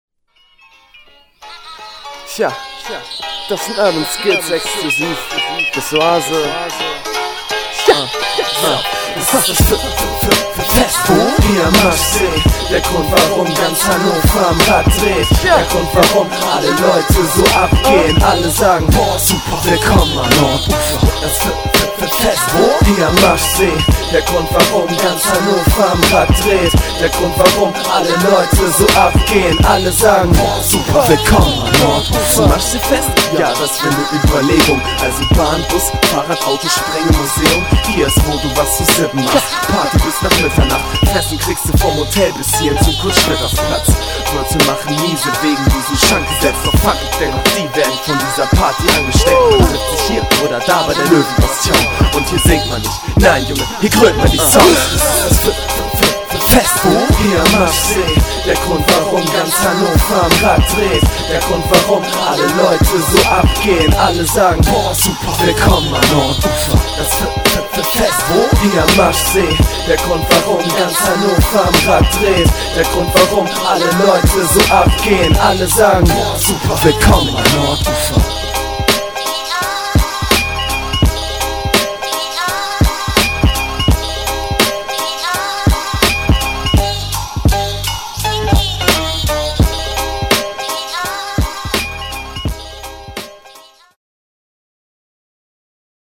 HipHop & Raggae Events / Live Concerts